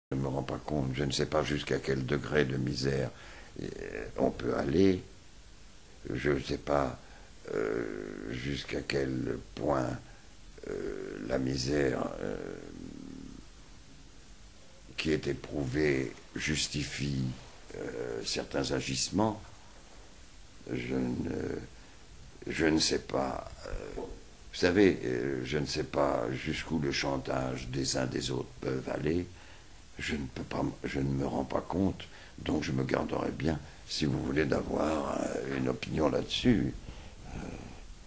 Profitant d’une pause déjeuner durant le tournage du long-métrage, Konstantínos Gavrás, plus connu sous le pseudonyme de Costa-Gavras, a accepté de répondre à quelques questions.
Ecoutez Costa-Gavras